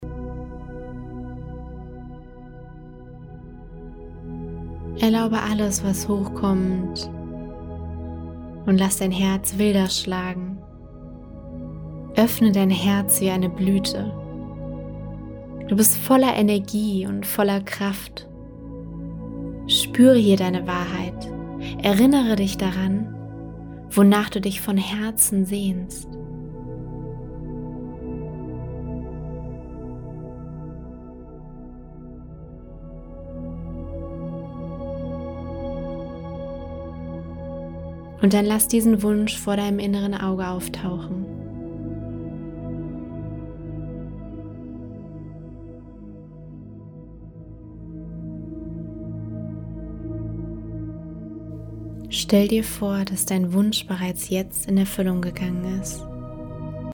In diesem Meditationspaket warten geführte Meditationen auf dich, die dich auf deinem Weg in deine innere Welt begleiten werden. Die Meditationen dienen dir, um dich für deine Träume zu öffnen und im Einklang mit dem Universum zu manifestieren.